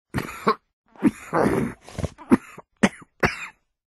Der innere Ghul: Audiodialoge